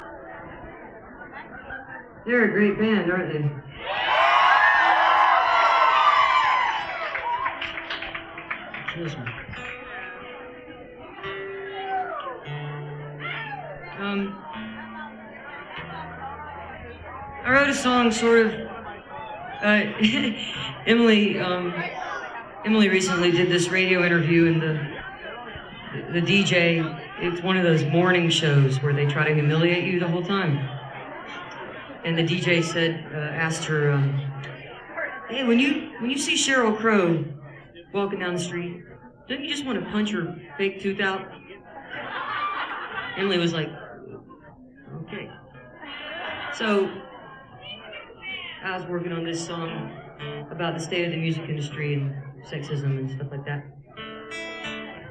cat's cradle - chapel hill, north carolina
09. introduction (0:55)